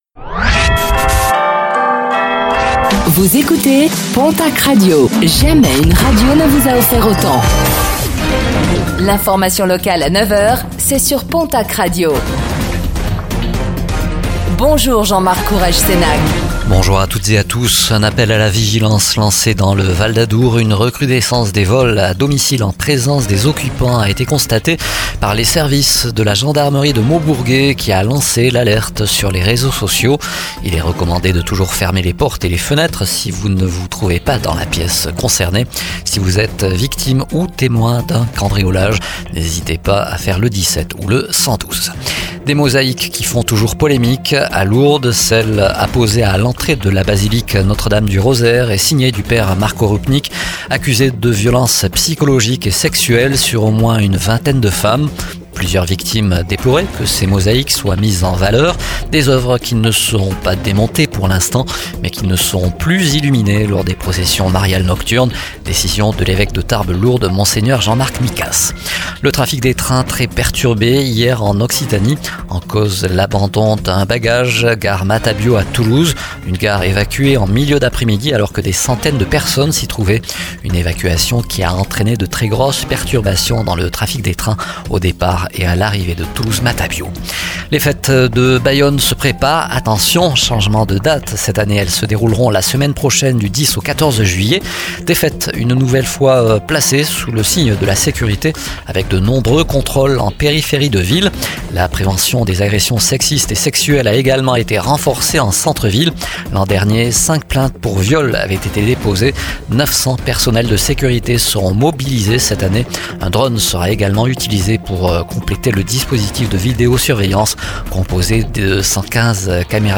09:05 Écouter le podcast Télécharger le podcast Réécoutez le flash d'information locale de ce jeudi 04 juillet 2024